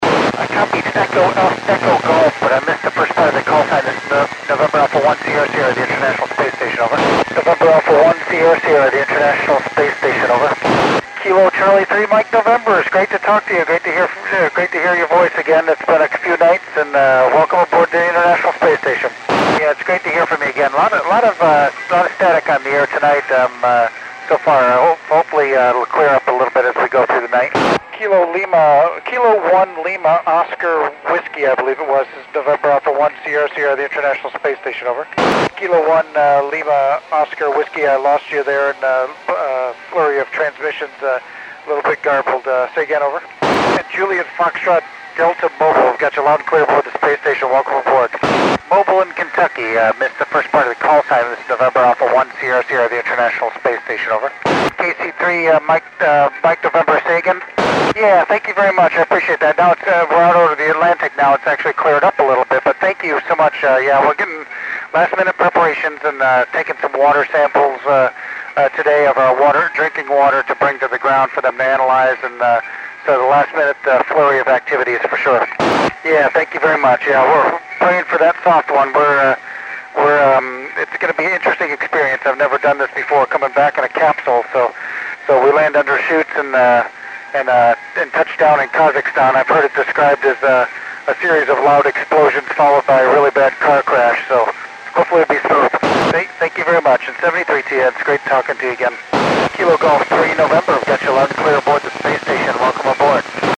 Col. Doug Wheelock works U.S. stations on 23 November 2010 at 1839 UTC.